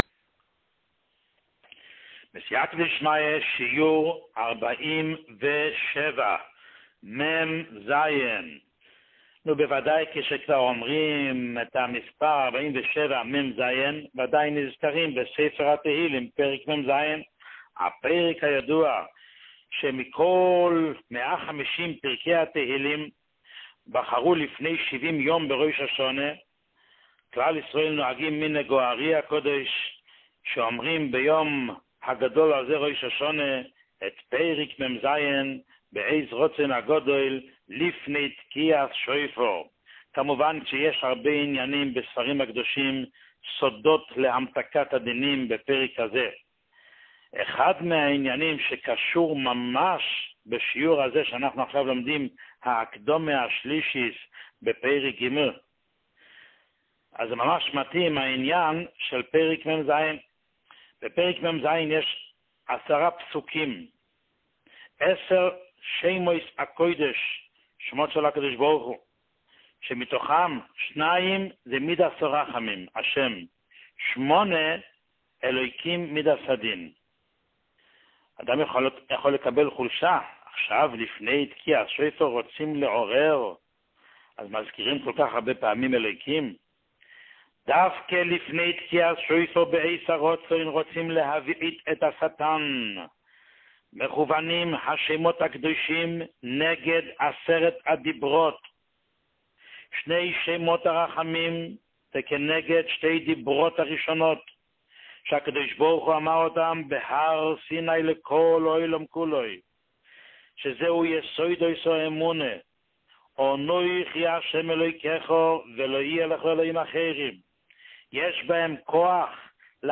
שיעורים מיוחדים
שיעור 47